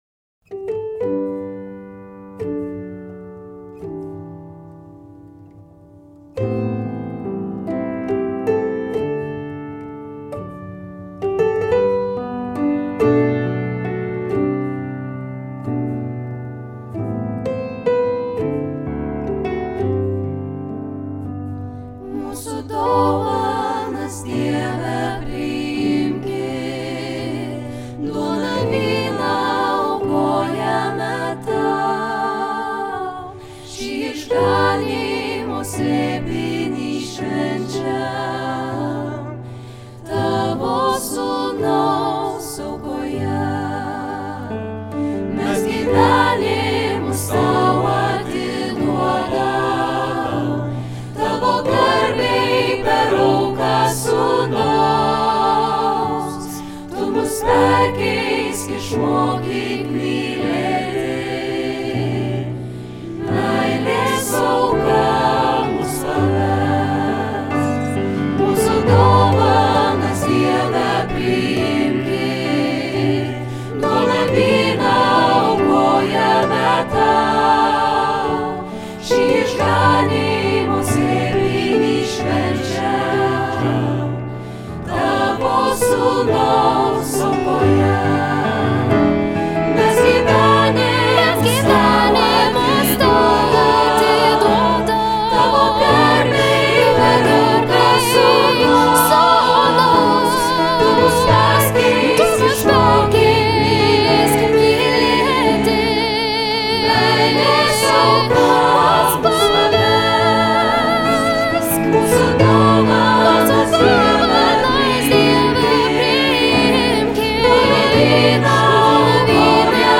Choras: